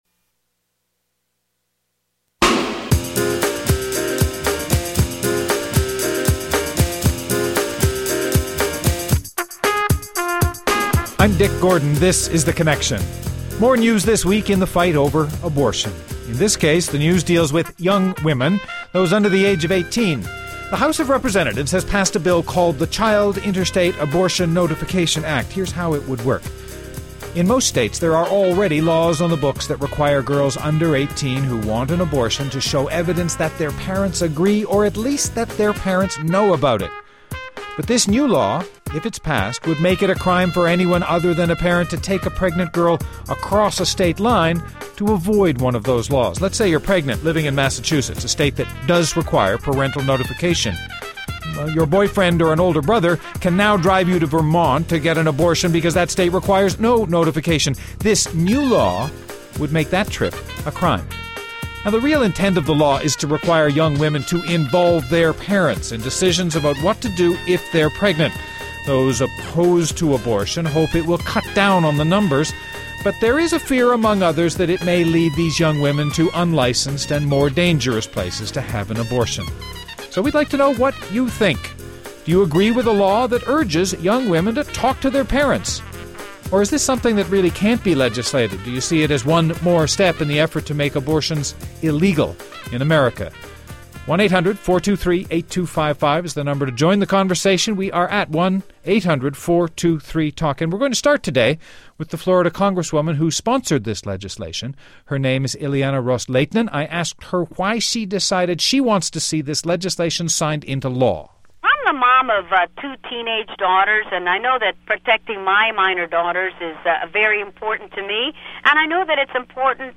Ileana Ros-Lehtinen, Republcian Congresswoman from Florida